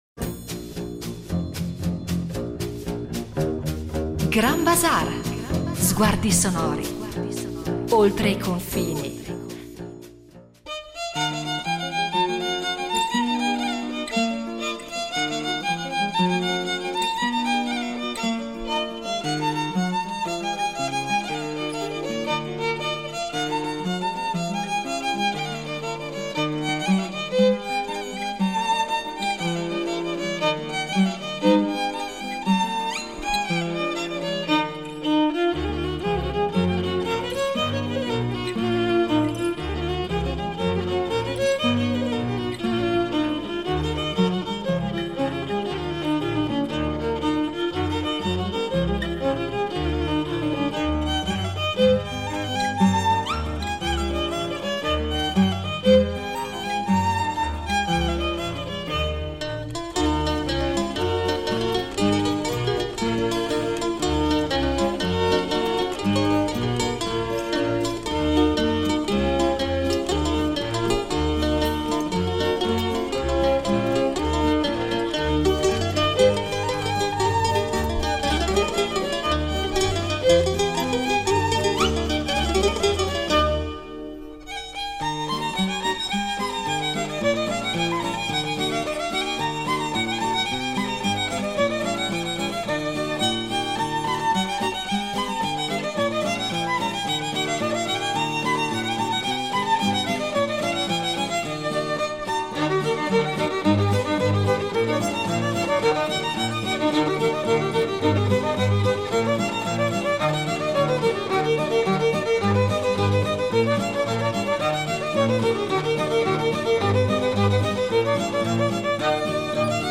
Il violino nella musica popolare
È un violino che accompagna danze, processioni, feste e malinconie.
Attraverso racconti e registrazioni scopriremo come il violino sia diventato la voce del popolo, capace di reinventarsi in ogni terra e in ogni tradizione.